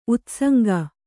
♪ utsaŋg